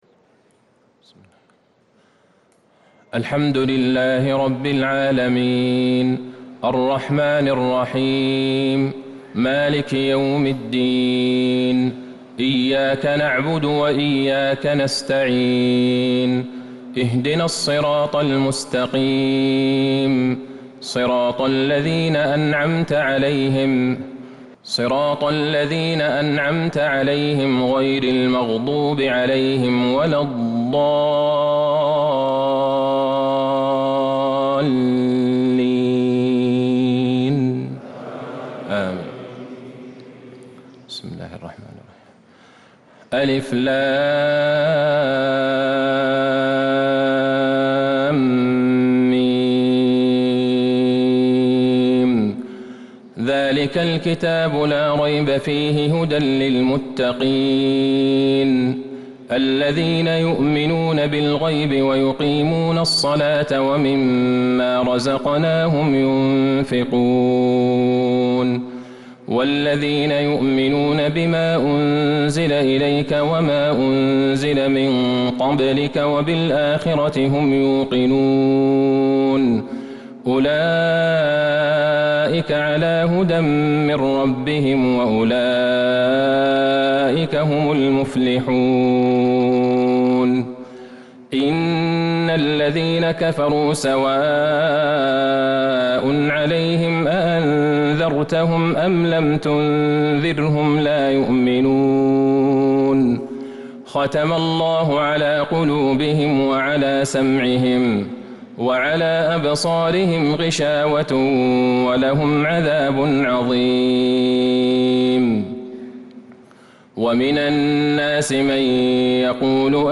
صلاة التراويح ليلة 29 رمضان 1443 للقارئ عبدالله البعيجان - الثلاث التسليمات الاولى صلاة التهجد